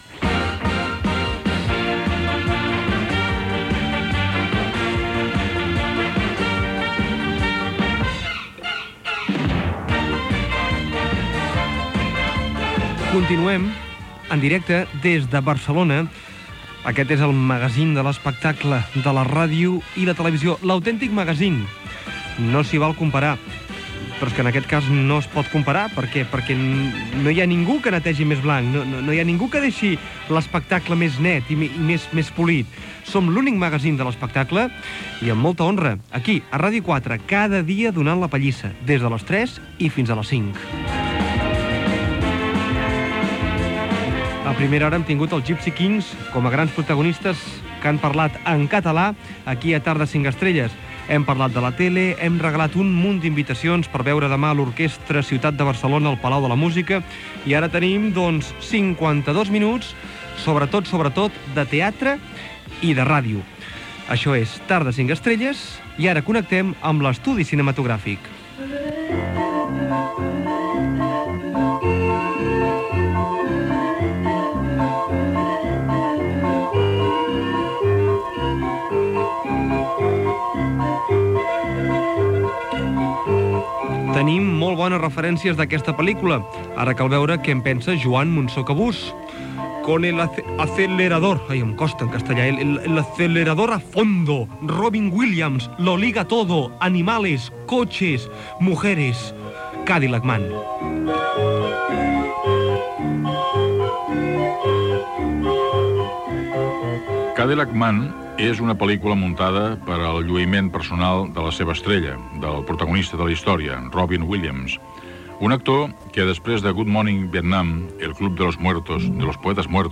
Sintonia del programa
Gènere radiofònic Entreteniment